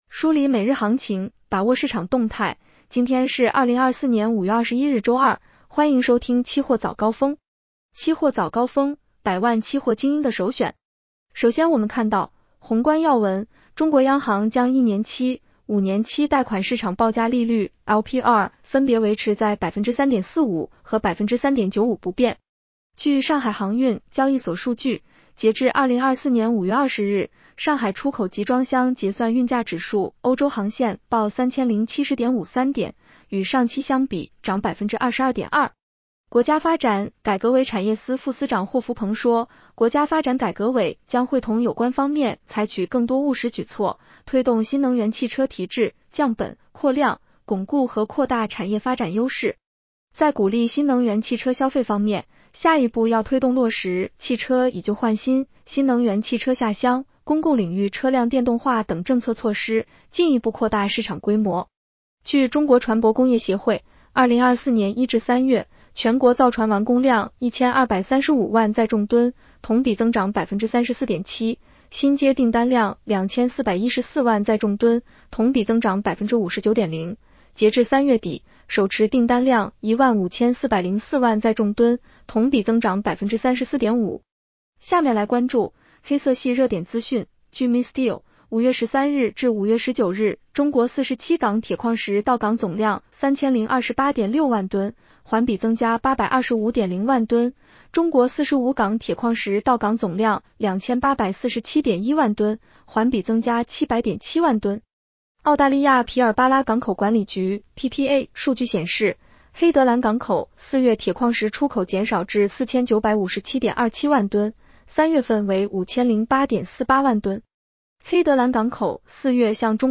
期货早高峰-音频版